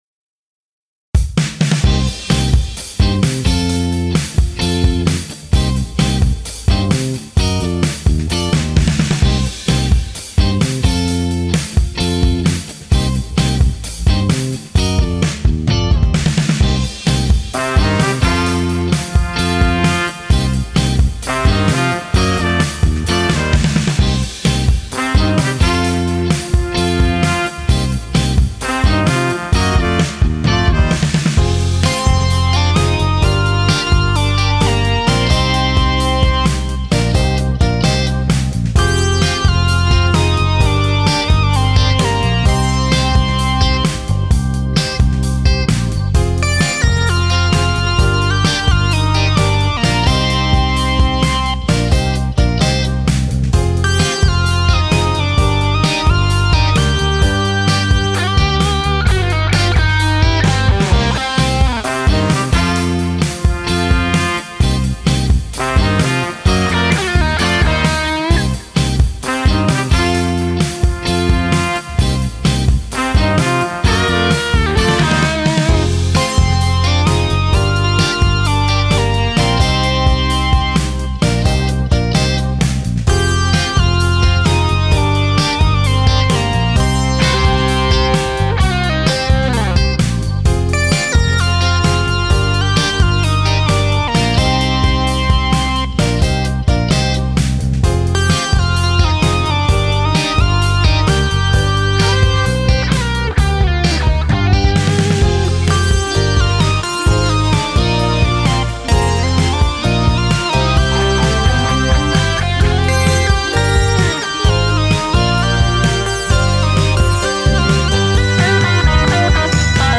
Just Alto, Tenor and Baritone saxes.
For rhythm guitar I used my red Strat while my white Tele handled the leadwork.
Once again we used a Logic Pro sideman for drums and percussion.